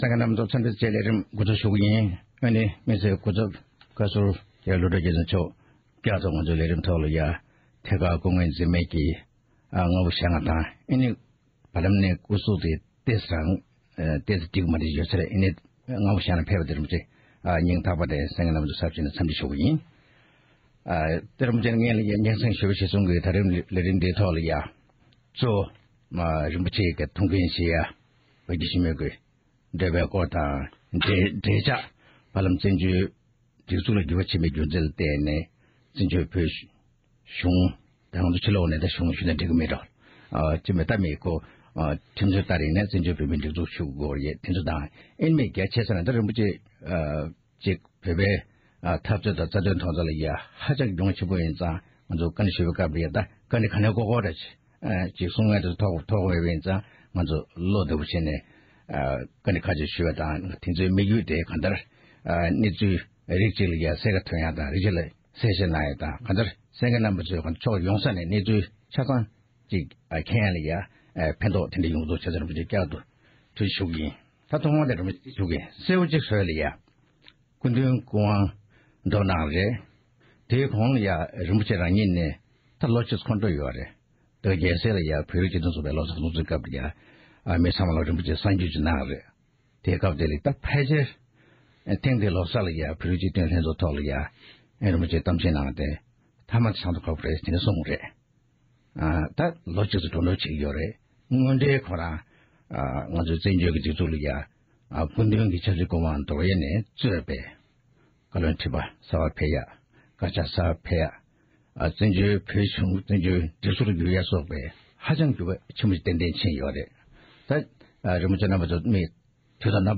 ༸གོང་ས་མཆོག་གི་དམིགས་བསལ་སྐུ་ཚབ་རྒྱ་རི་རིན་པོ་ཆེའི་ལྷན་གླེང་བ།